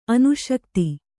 ♪ anuśakti